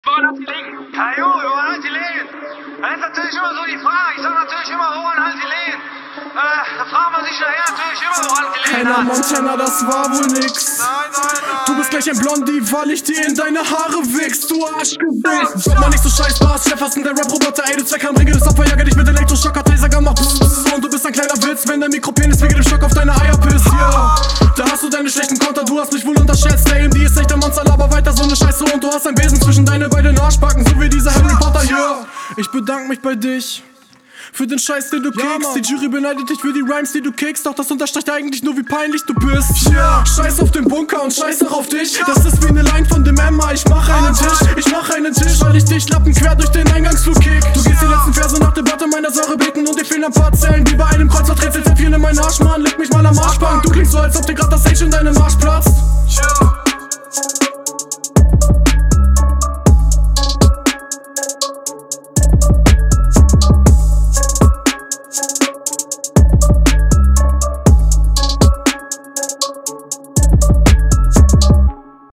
"Woran hats jelegen" ebenfalls ein sehr schöner Einspieler!